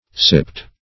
Sip \Sip\ (s[i^]p), v. t. [imp. & p. p. Sipped (s[i^]pt); p.